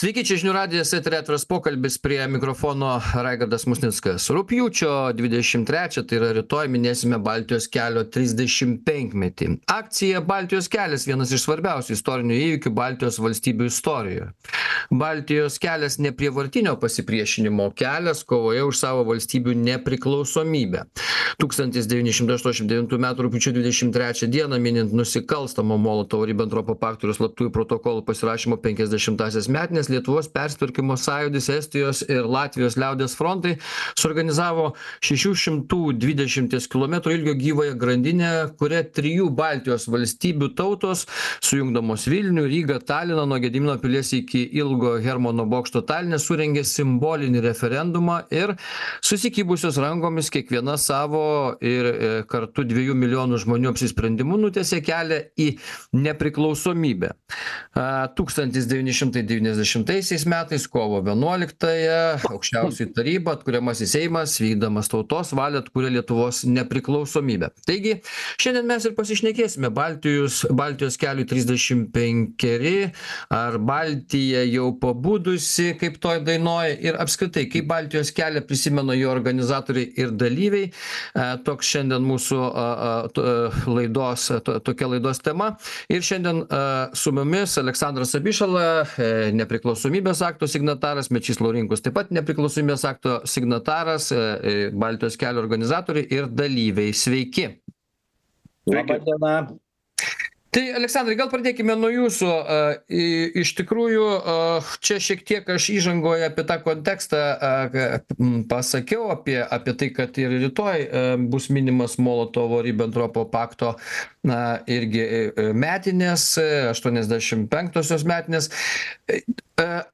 Diskutuoja: Nepriklausomybės akto signatarai Aleksandras Abišala ir Mečys Laurinkus.